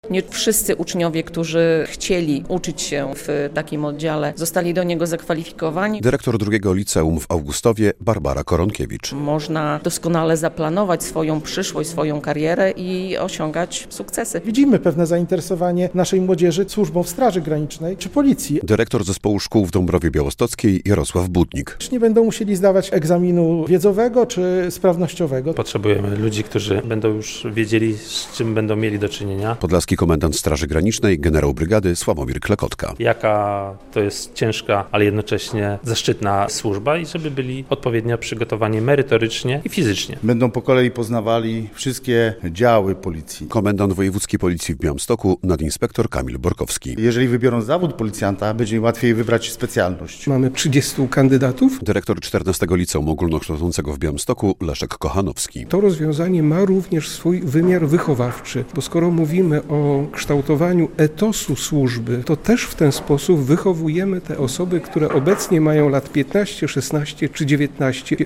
Bezpieczna przyszłość? Nowy model kształcenia młodzieży w służbach mundurowych - relacja